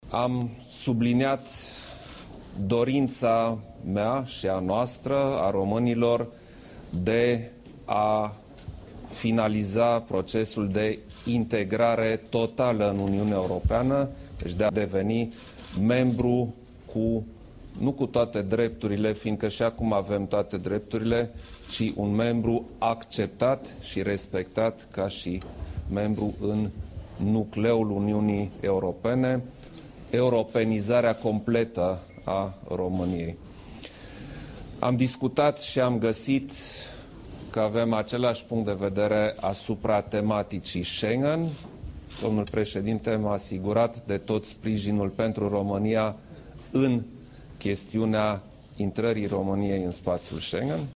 Bruxellesul recunoaşte progresele făcute pentru consildarea insituţiilor statului, astfel încât Mecanismul de Cooperare şi Verificare ar putea fi în curând finalizat, a adăugat Klaus Iohannis: